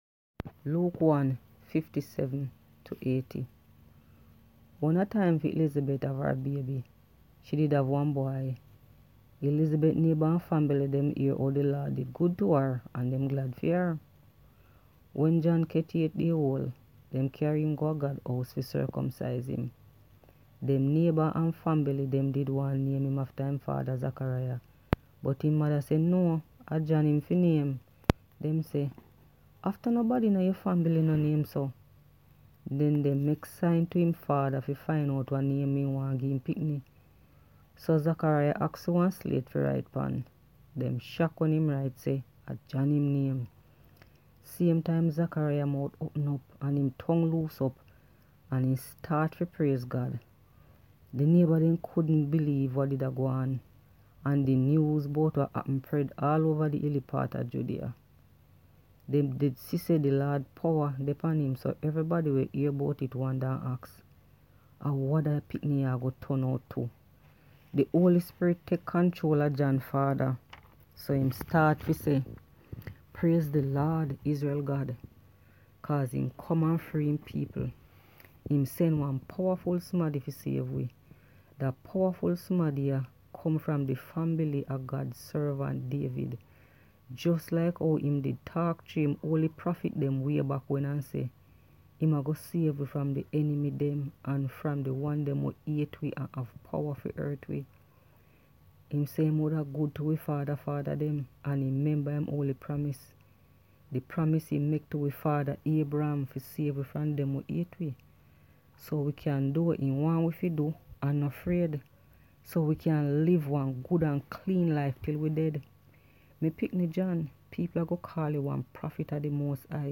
Enjoy scripture reading from Di Jamiekan Nyuu Testiment